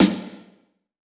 SNARE 107.wav